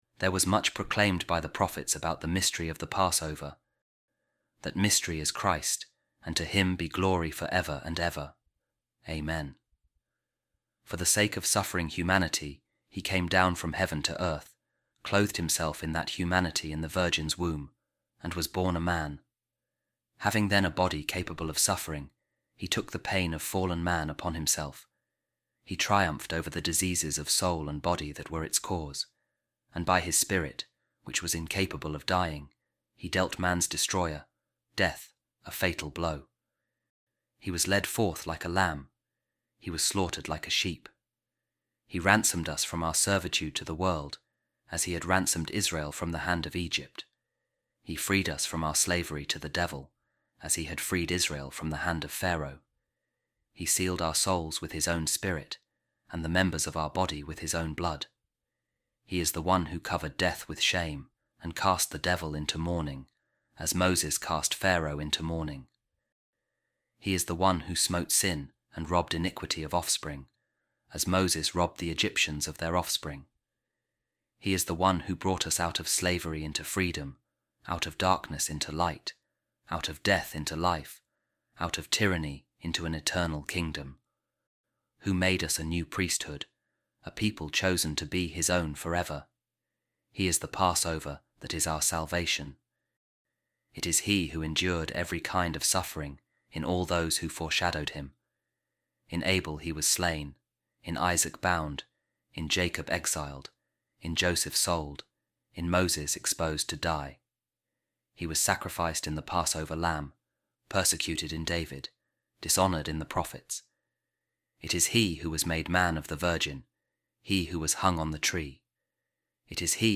Office Of Readings | Thursday Of Holy Week | Maundy Thursday | A Reading From The Homily Of Saint Melito Of Sardis On The Pasch